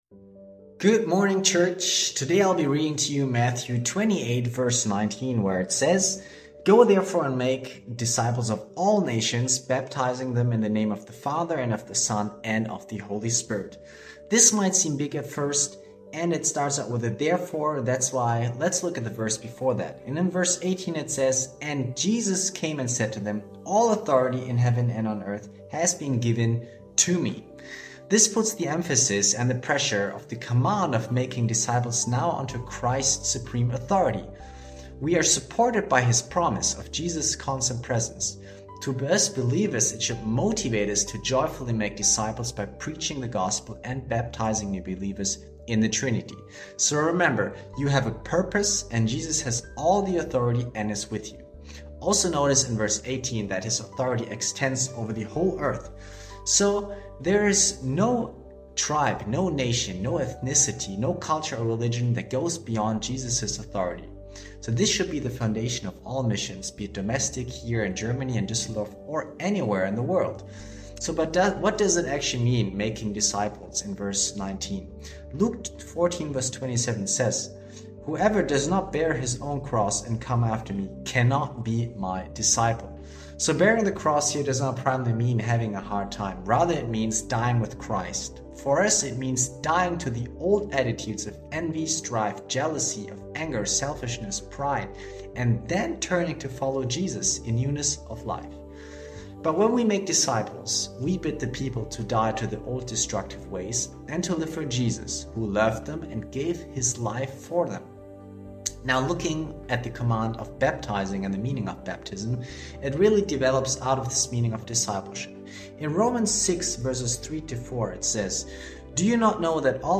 Tag 18 der Andacht zu unseren 21 Tagen Fasten & Gebet